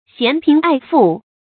嫌貧愛富 注音： ㄒㄧㄢˊ ㄆㄧㄣˊ ㄞˋ ㄈㄨˋ 讀音讀法： 意思解釋： 嫌：厭惡。